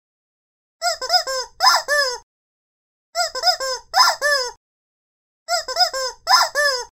screamingChicken.mp3